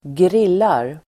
Uttal: [²gr'il:ar]
grillar.mp3